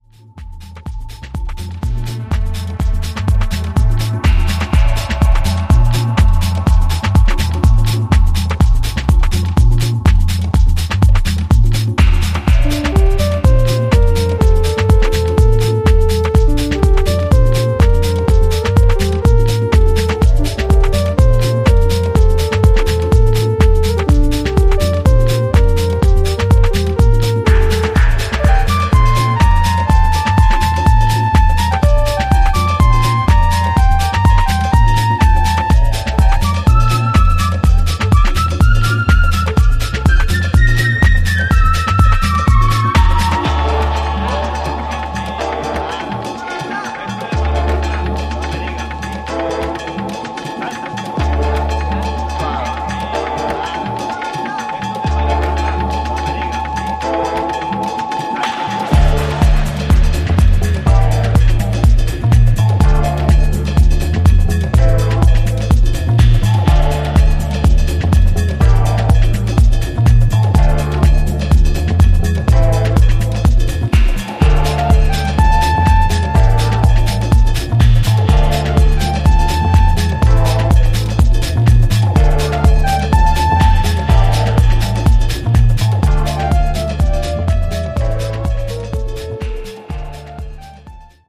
ジャンル(スタイル) DEEP HOUSE / AFRO